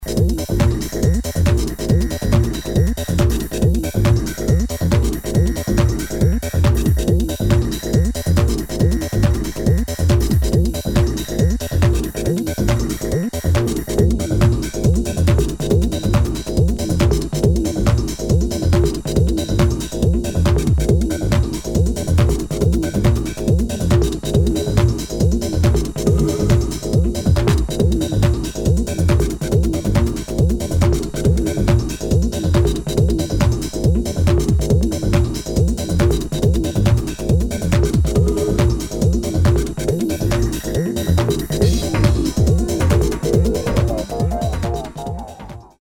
[ TECHNO / ACID / TRANCE ]